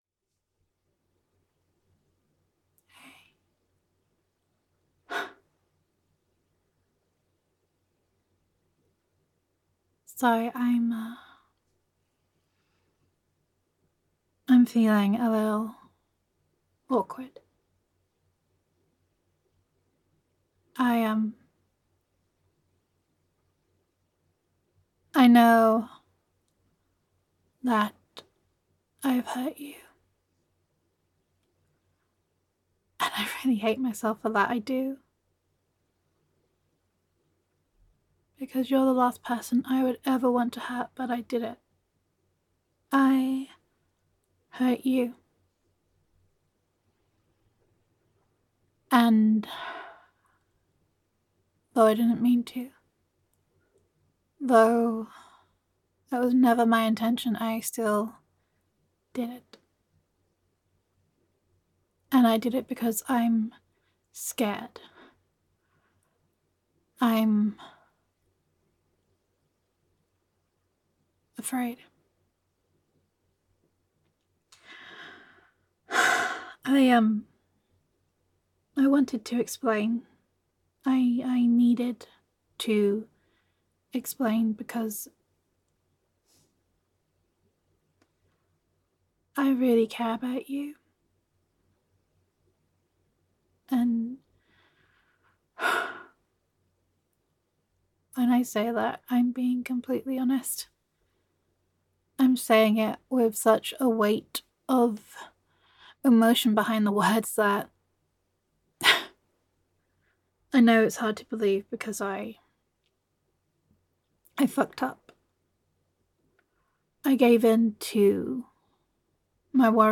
[F4A]
[Girlfriend Roleplay]